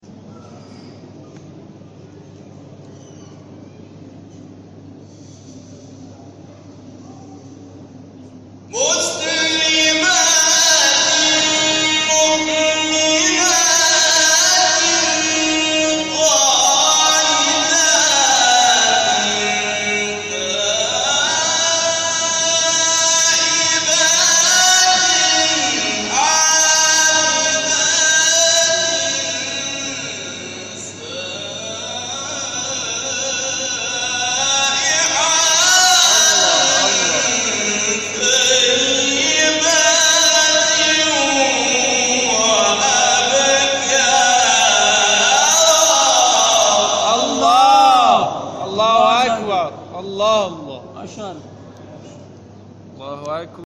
گروه شبکه اجتماعی: فرازهای صوتی از قاریان ممتاز و تعدادی از قاریان بین‌المللی کشورمان را می‌شنوید.